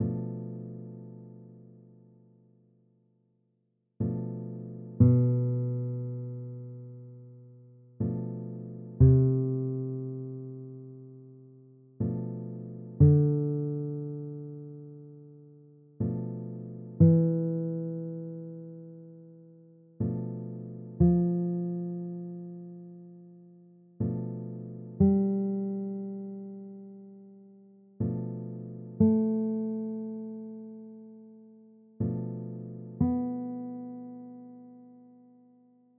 Below, you can listen to each mode played note by note over the matching diatonic 7th chord.  The chord will repeat every measure.
B Locrian Bm7b5 B-C-D-E-F-G-A-B